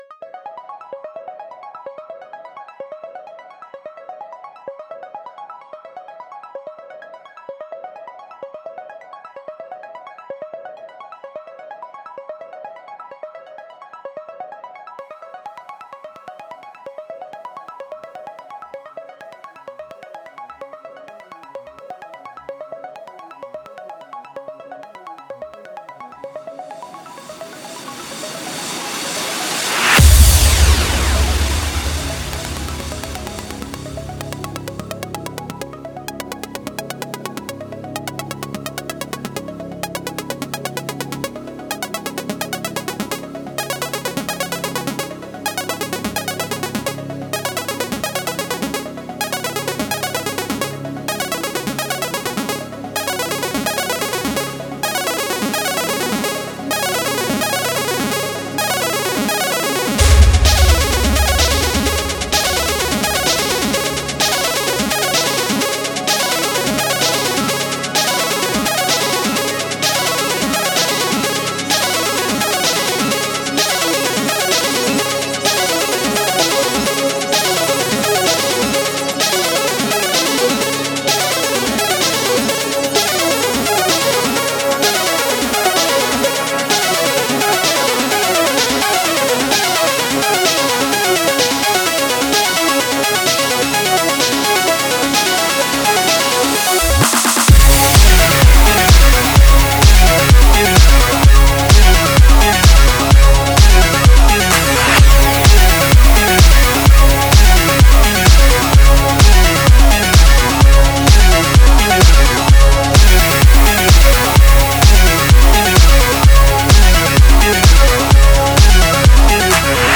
the only instrumental song